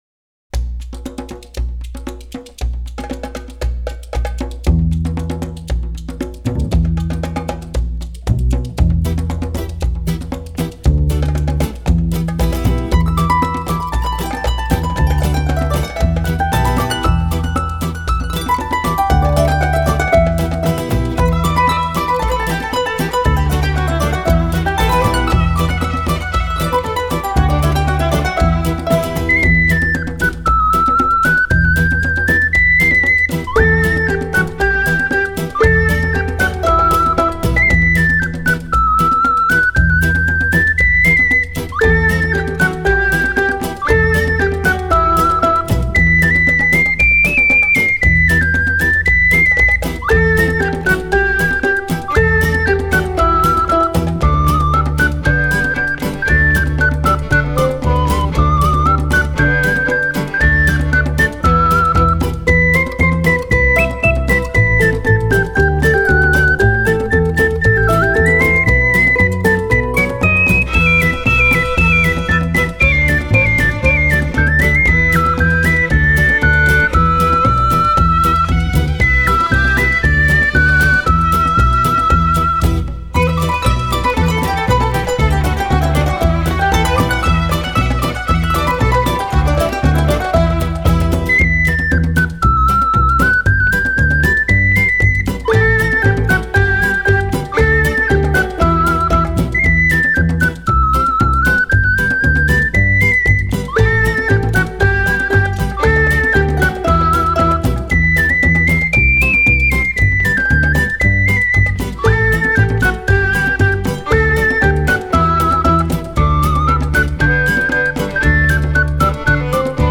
Genre: Age New.